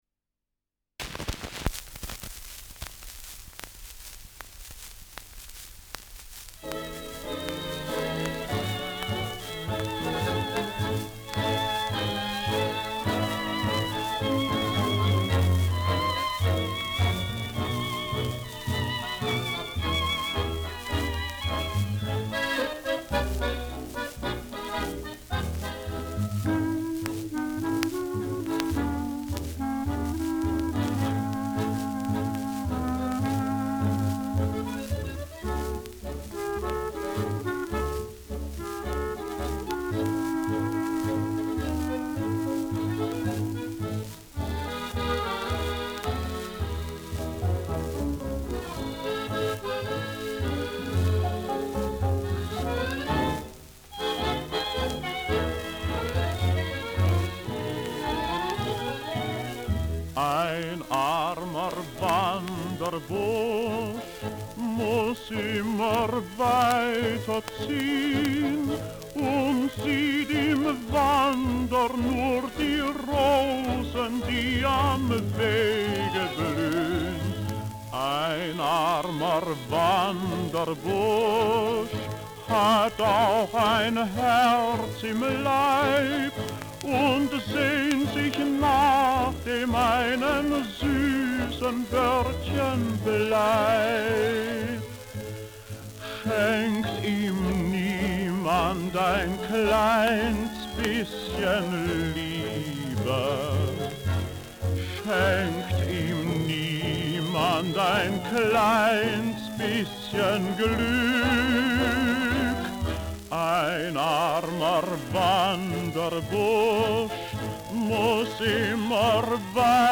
Schellackplatte
Operettenmelodie* FVS-00011